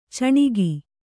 ♪ caṇigi